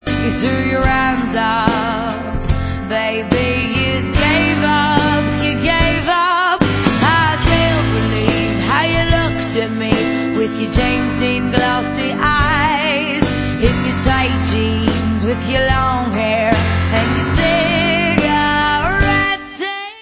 Dance